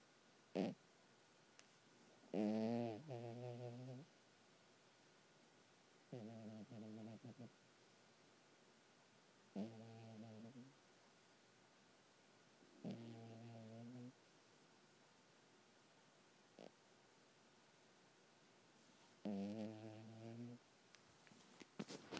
Cat snoring.